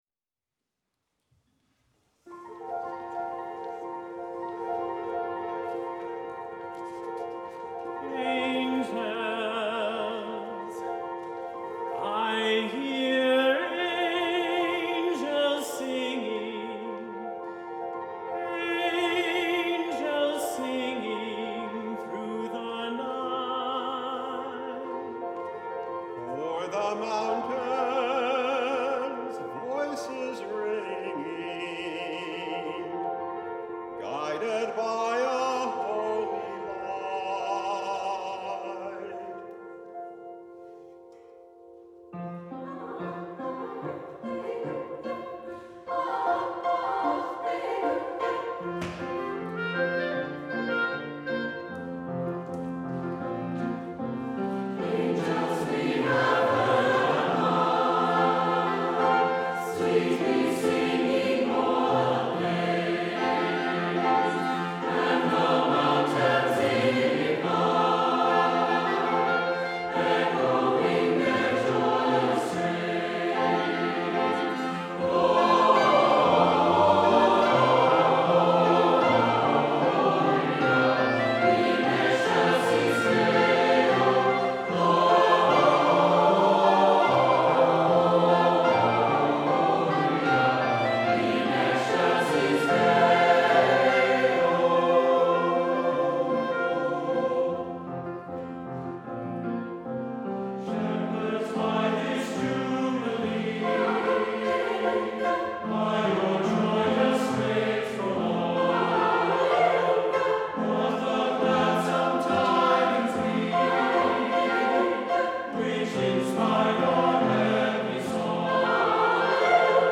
for SATB Chorus, Clarinet, and Piano (2017)